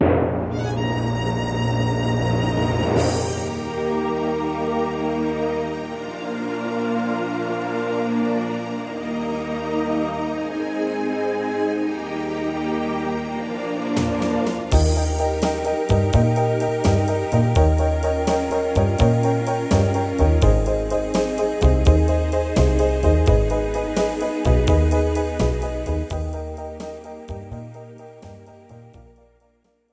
This is an instrumental backing track cover.
• Key – E♭
• No Fade